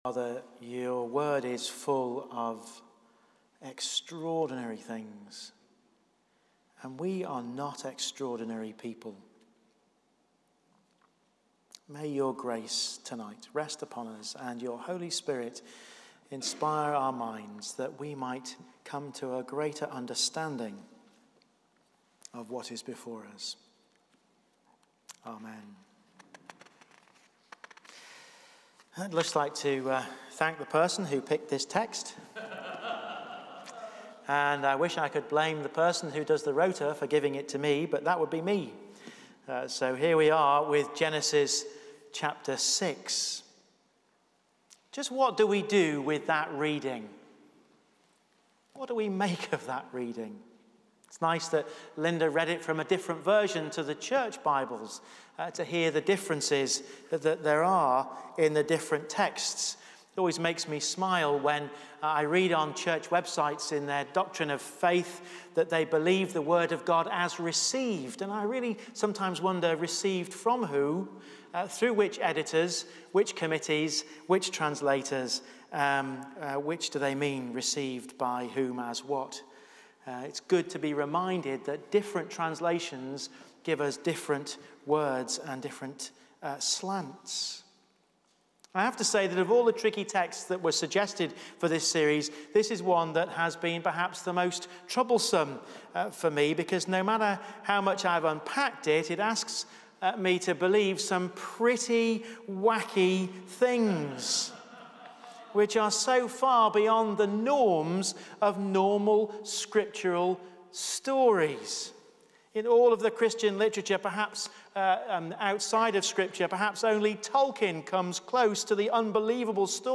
Tricky texts Passage: Genesis 6: 1-8 Service Type: Evening Praise « What price peace?